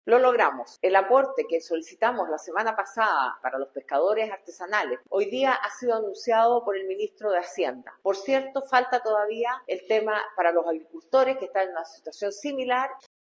La presidenta de la colectividad, la senadora Paulina Vodanovic, valoró las medidas anunciadas, pero advirtió que existen otros sectores gravemente afectados que también requieren atención.